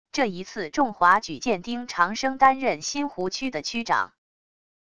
这一次仲华举荐丁长生担任新湖区的区长wav音频生成系统WAV Audio Player